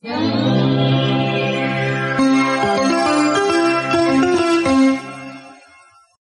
Senyal de desconnexió